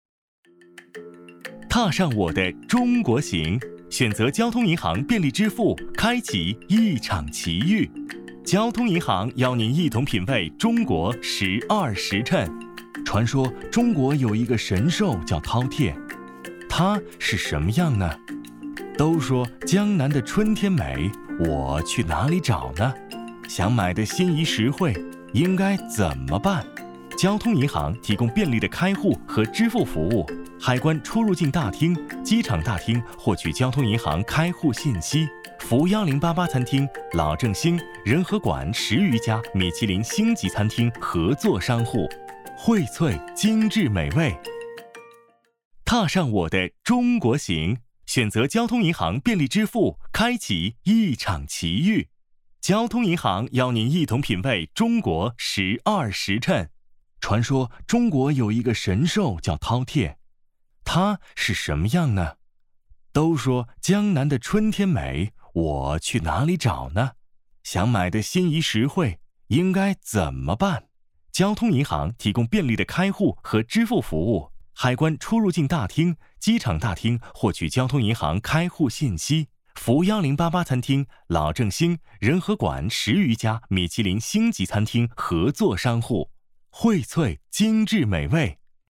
国语配音
声音可大气、磁性，年轻，可录中英文专题、广告、飞碟说、游戏、模仿、角色等
男384-中文-飞碟说-交行.mp3